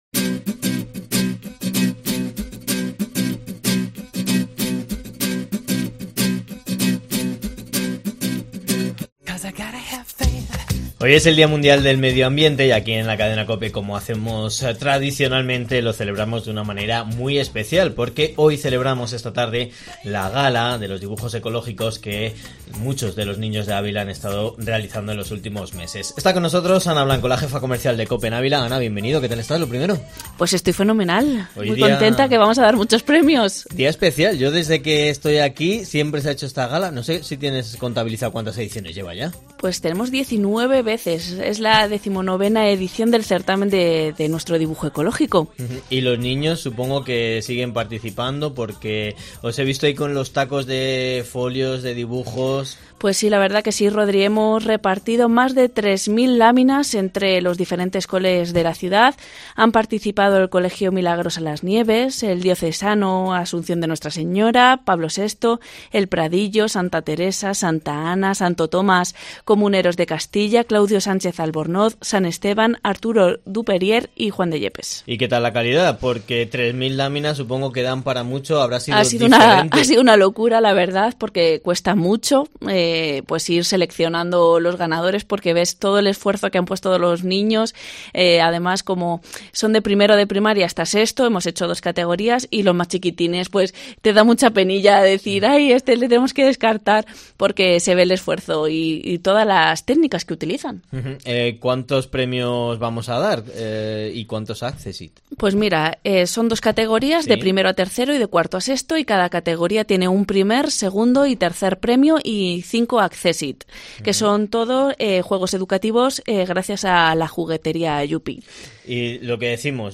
Gala de entrega de premios del certamen de Escolar de Dibujo Ecológico de COPE Ávila